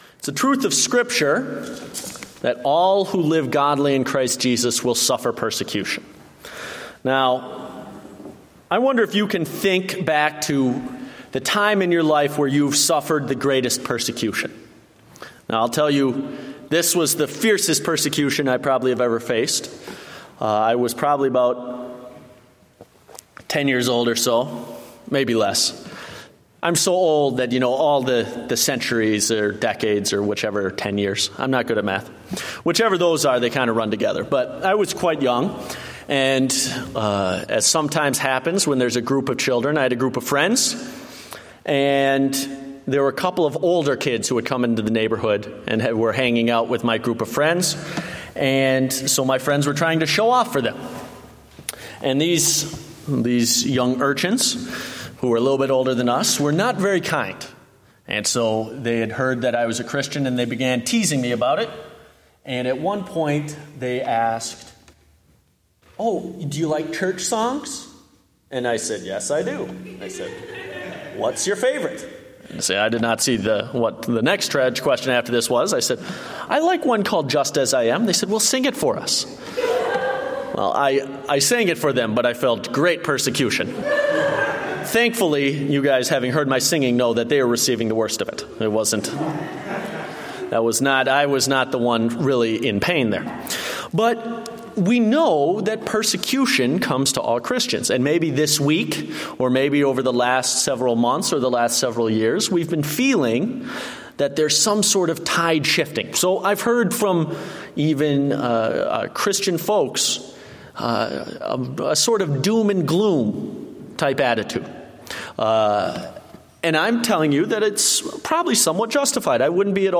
Date: June 28, 2015 (Evening Service)